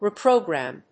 /riˈproˌgræm(米国英語), ri:ˈprəʊˌgræm(英国英語)/